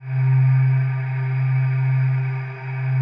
PAD 48-1.wav